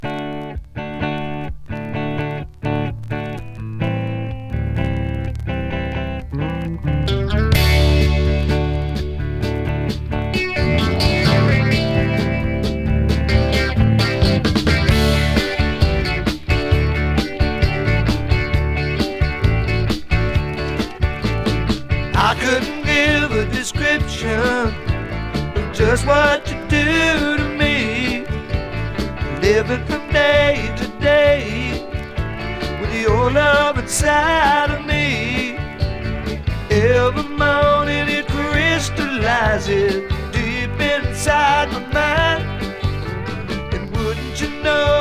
Rock, Country Rock, Blues Rock　USA　12inchレコード　33rpm　Stereo